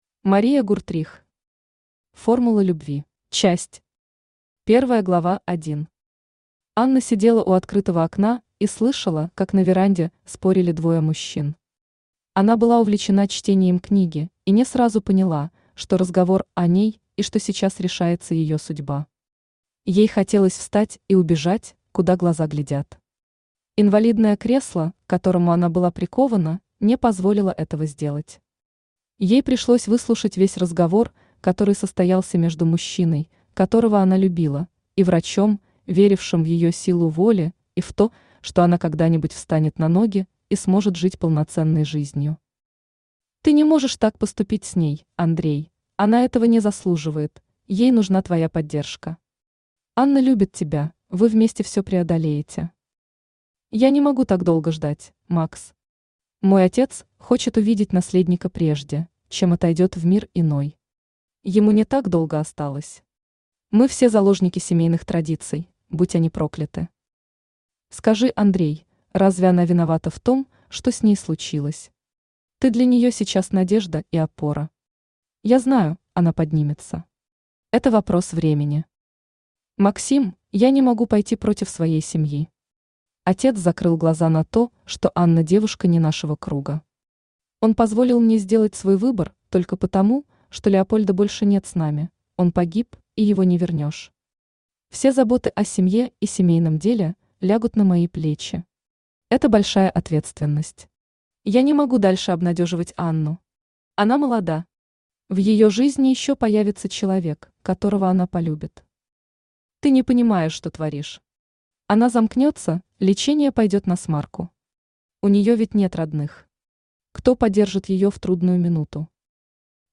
Aудиокнига Формула любви Автор Мария Гуртрих Читает аудиокнигу Авточтец ЛитРес.